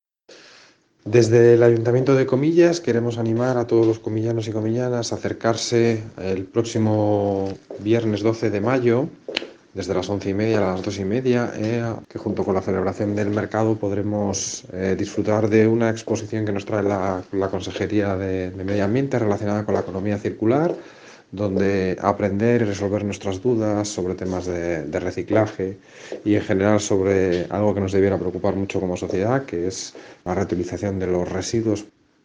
Audio del concejal de Medio Ambiente, Ramón Gelabert